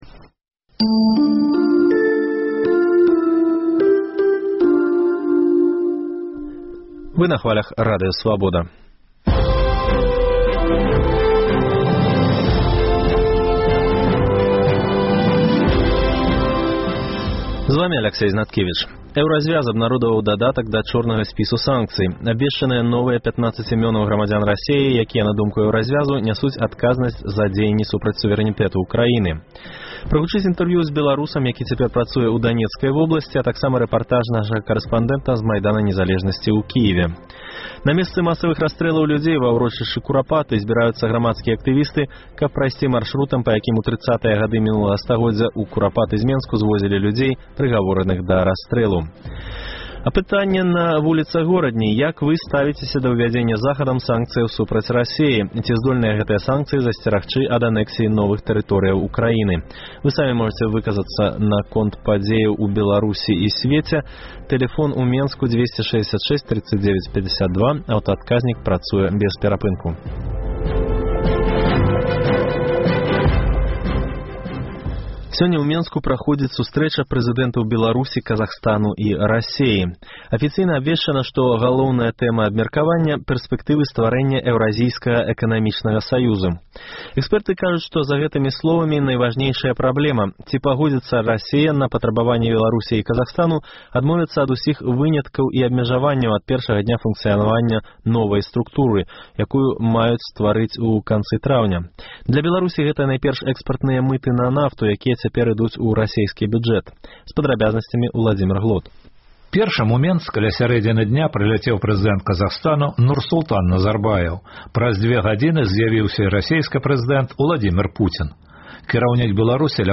Прагучыць інтэрвію зь беларусам, які цяпер працуе ў Данецкай вобласьці, а та